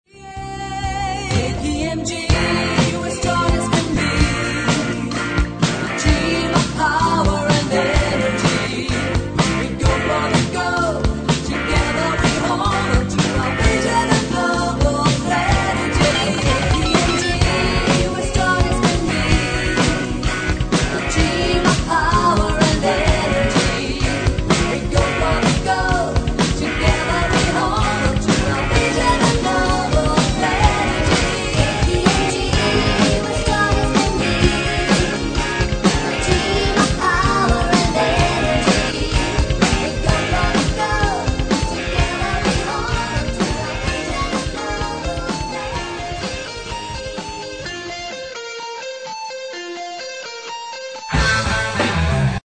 Hard Rock Mix